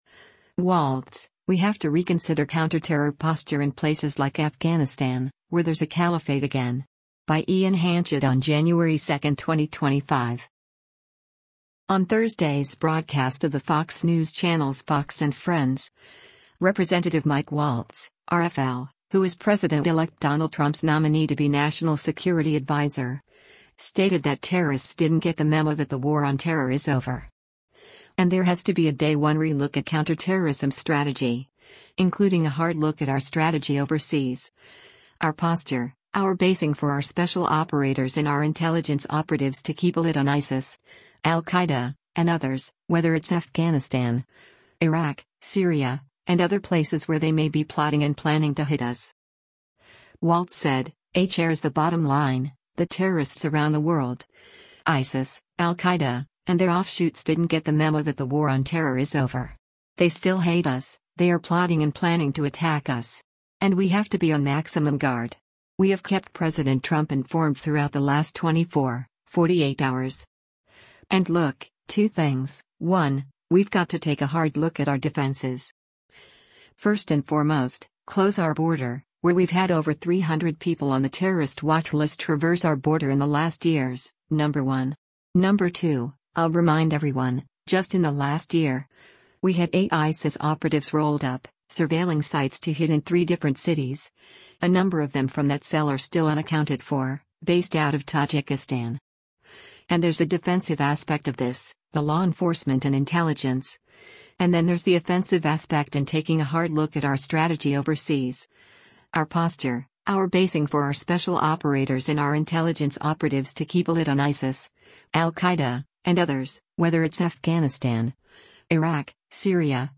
On Thursday’s broadcast of the Fox News Channel’s “Fox & Friends,” Rep. Mike Waltz (R-FL), who is President-Elect Donald Trump’s nominee to be national security adviser, stated that terrorists “didn’t get the memo that the war on terror is over.” And there has to be a “day one relook” at counterterrorism strategy, including “a hard look at our strategy overseas, our posture, our basing for our special operators and our intelligence operatives to keep a lid on ISIS, al-Qaeda, and others, whether it’s Afghanistan, Iraq, Syria, and other places where they may be plotting and planning to hit us.”